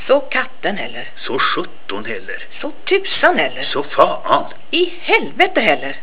Для прослушивания или скачивания звукового файла, содержащего произношение приведенных примеров, пожалуйста, нажмите на название соответствующего раздела.